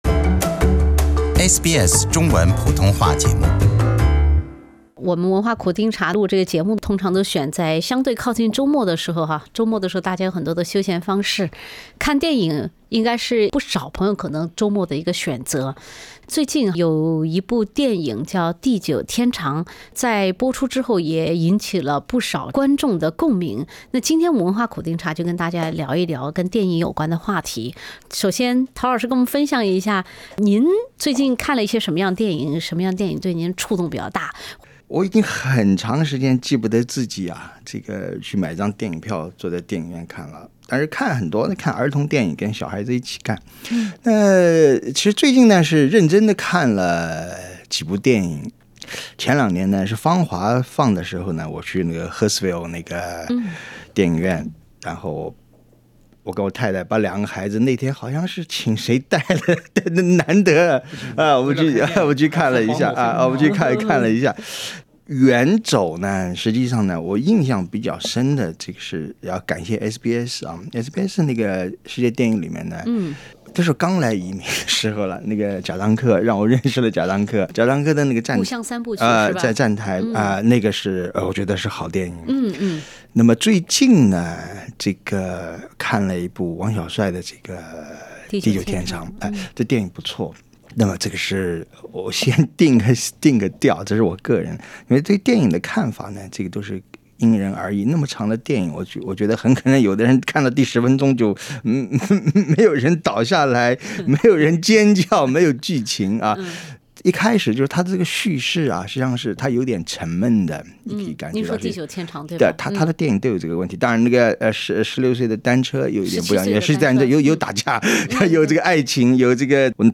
07:31 SBS 普通话电台 View Podcast Series Follow and Subscribe Apple Podcasts YouTube Spotify Download (13.78MB) Download the SBS Audio app Available on iOS and Android 你看过的电影，哪些让你最难忘？